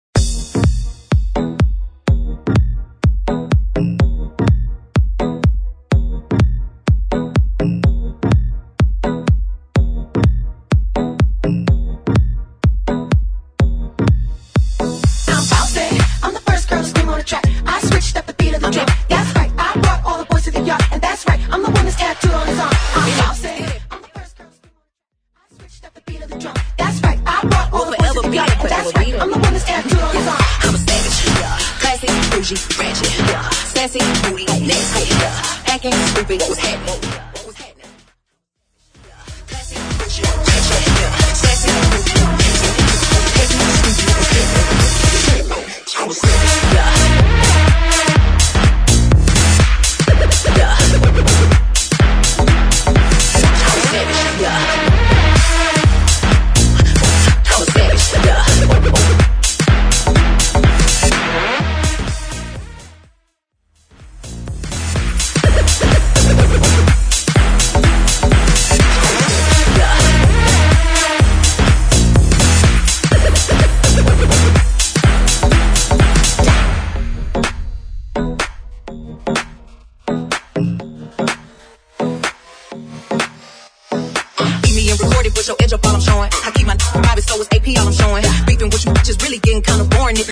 House Remix – Hype intro
DANCE , FUTURE HOUSE , HIPHOP